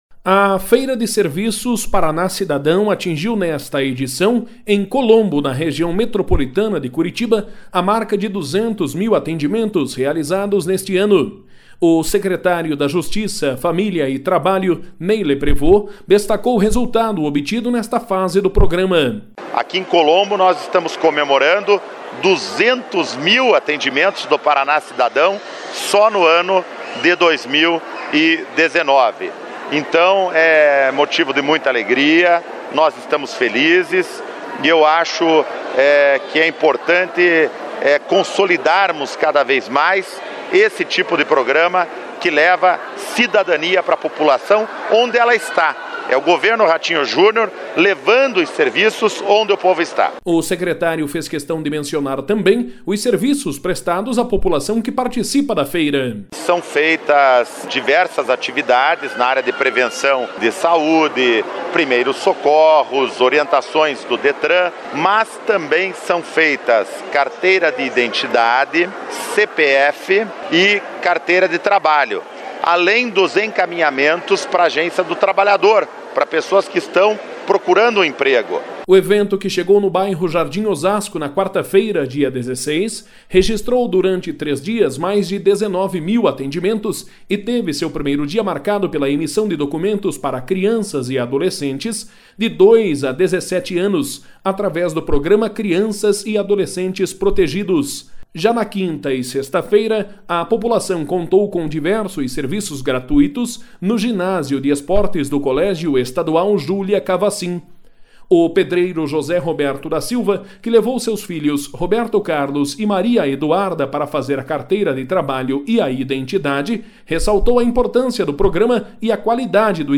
A feira de serviços Paraná Cidadão atingiu nesta edição, em Colombo, na Região Metropolitana de Curitiba, a marca de 200 mil atendimentos realizados neste ano. O secretário da Justiça, Família e Trabalho, Ney Leprevost destacou o resultado obtido nesta fase do programa.